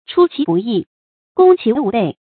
chū qí bù yì，gōng qí wú bèi
出其不意，攻其无备发音